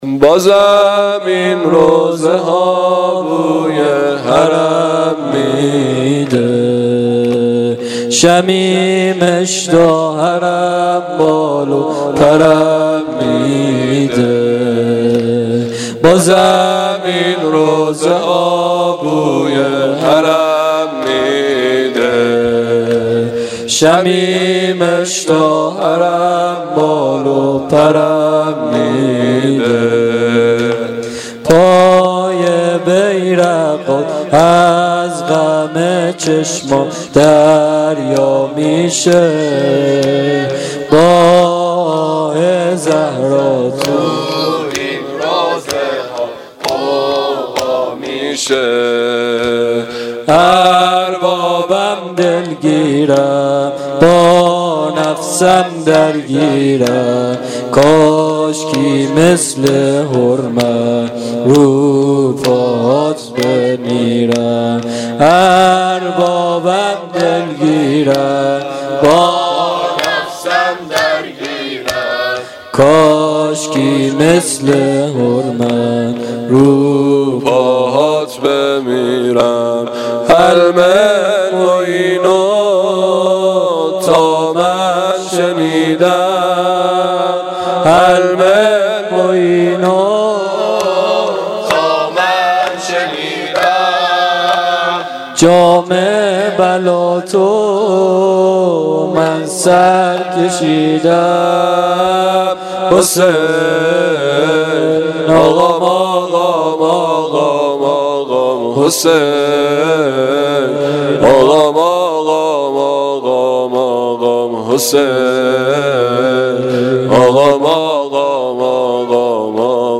دم پایانی شب سوم محرم الحرام 1395